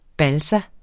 Udtale [ ˈbalsa ]